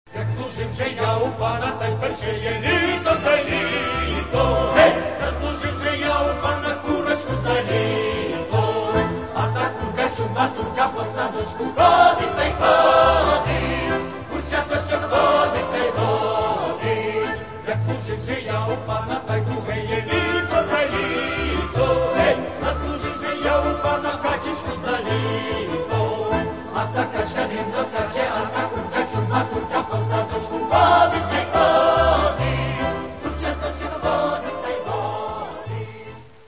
Українські народні жартівливі пісні.